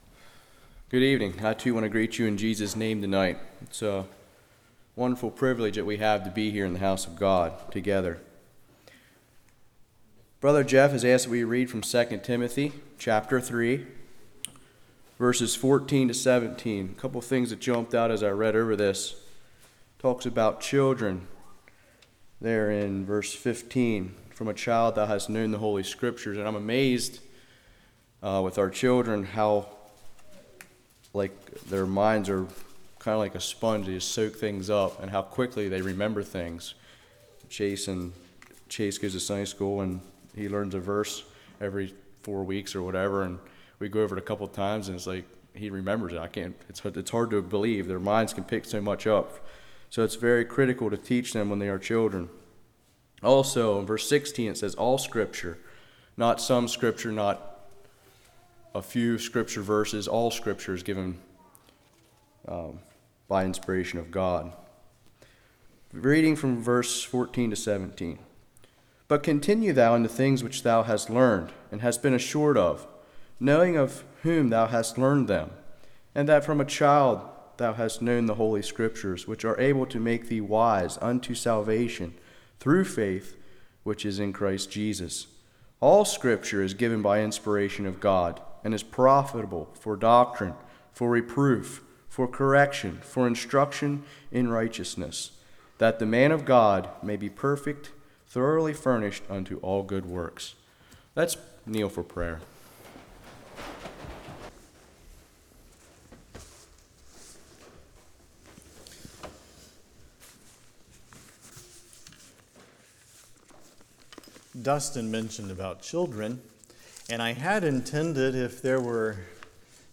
Passage: 2 Timothy 3:14-17 Service Type: Evening